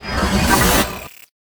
arrowpop.ogg